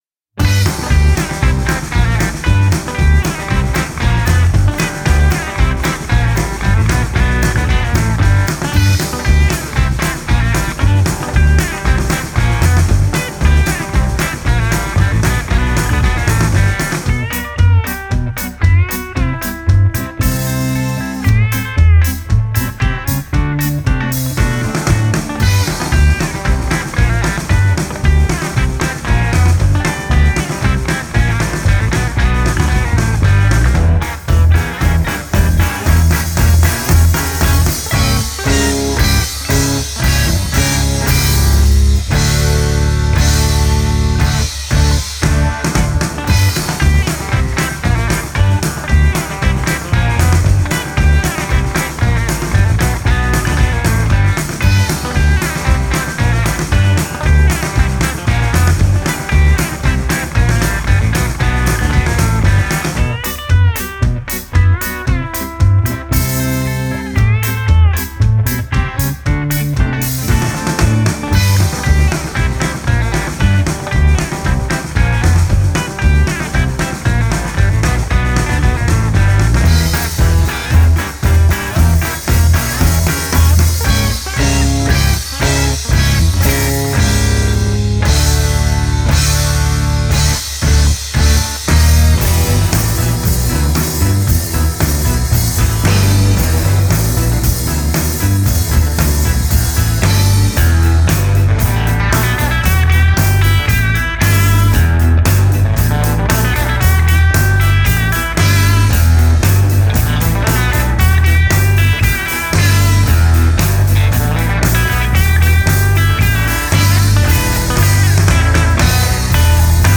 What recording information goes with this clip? Recorded at Lincoln County Social Club